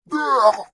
对话，痛苦的叫声 " 对话，痛苦的叫声，大声，E
我自己的声音，下降了20％。
Tag: 尖叫 语音 疼痛 痛苦 痛苦 声音 心疼 对话 尖叫 痛苦 Yelp长久 疼痛 对话